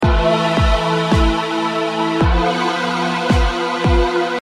Tag: 140 bpm Dubstep Loops Bass Wobble Loops 2.31 MB wav Key : Unknown